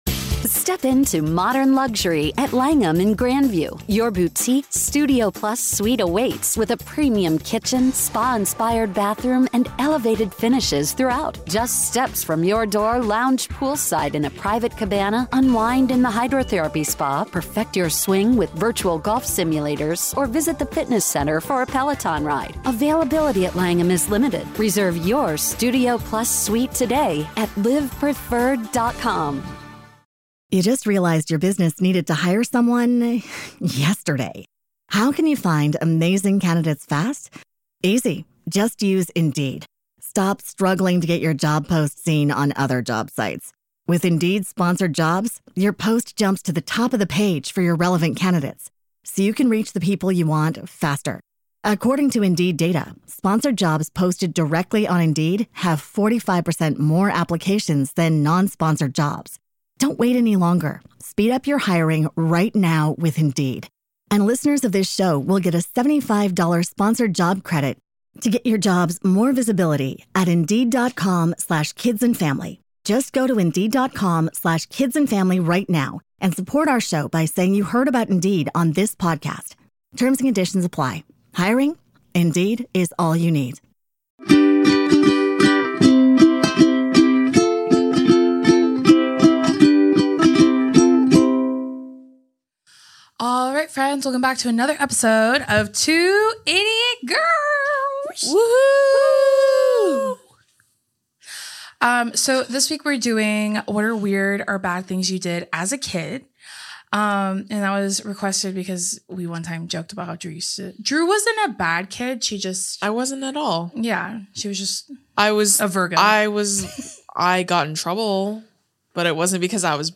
this week we read and giggled and winced??? at some of the weird or bad things y'all did as kids!